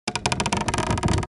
Звуки растущего дерева
На этой странице представлены редкие звуки растущего дерева — от едва уловимого шепота молодых побегов до глубоких вибраций старого ствола.